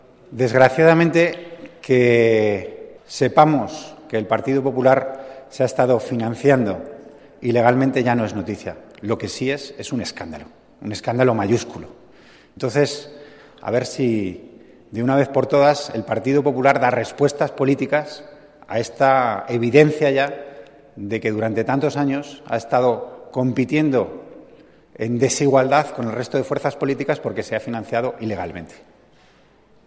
Declaraciones de Antonio Hernando después de que el juez Ruz afirmara que el PP se benefició de dinero procedente de actividades ilegales 25/09/2014